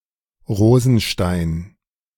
Rosenstein (German pronunciation: [ˈʁoːzn̩ˌʃtaɪ̯n] ⓘ) is surname of German and Yiddish origin.